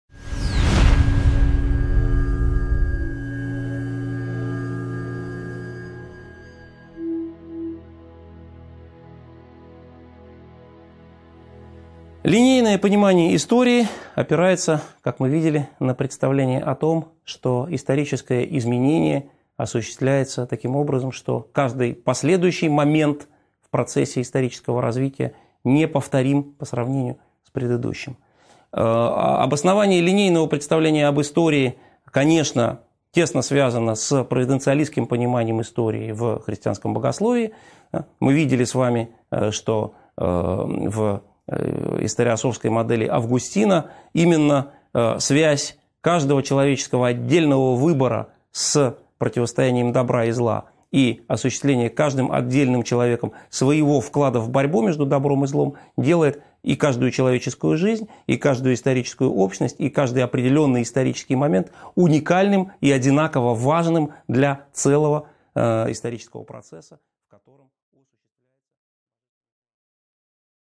Аудиокнига 13.7 Теория прогресса | Библиотека аудиокниг